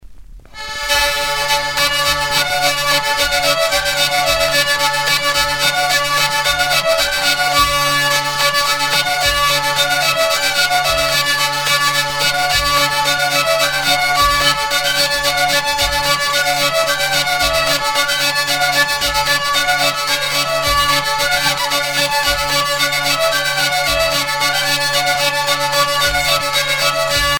Anost
danse : bourree
Pièce musicale éditée